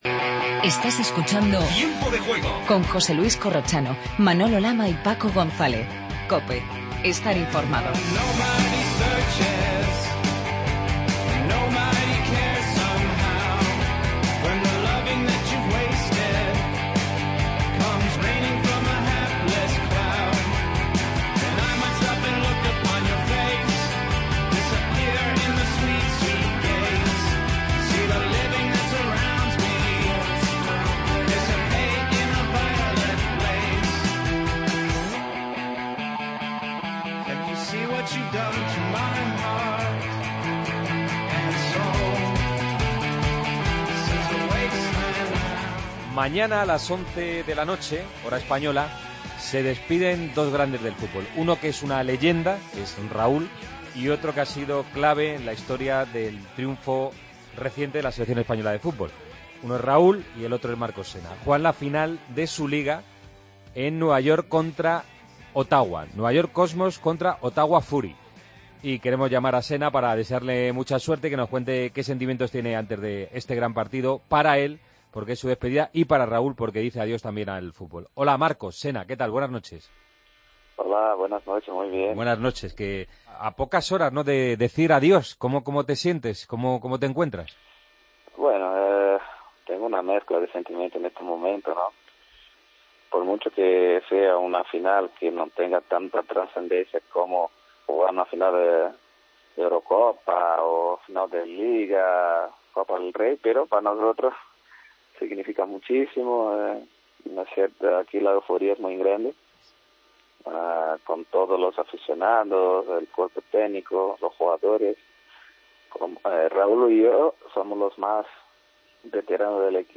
AUDIO: Hablamos con Marcos Senna.